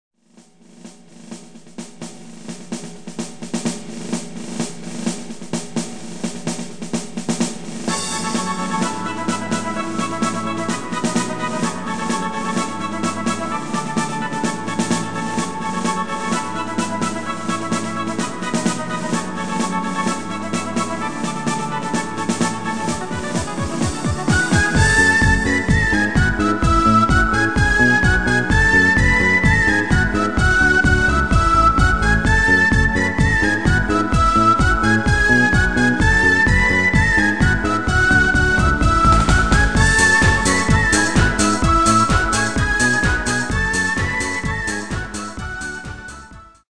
Extended MIDI File Euro 12.00
Demo's zijn eigen opnames van onze digitale arrangementen.